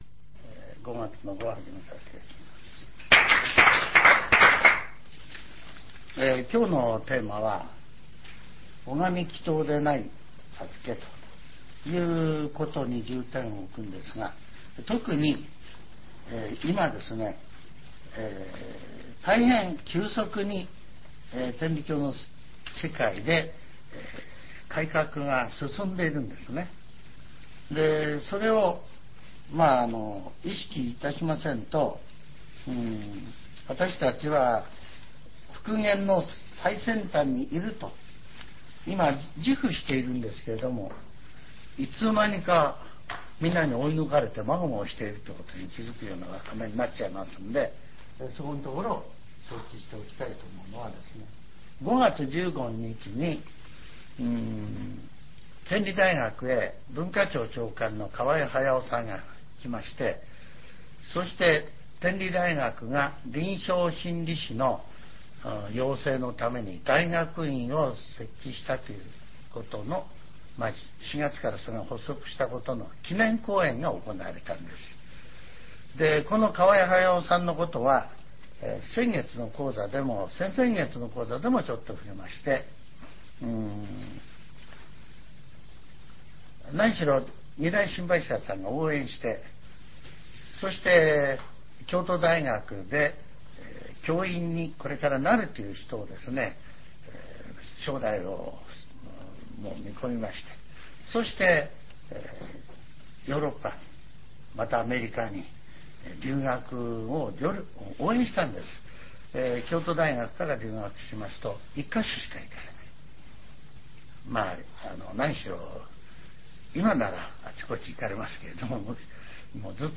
全70曲中33曲目 ジャンル: Speech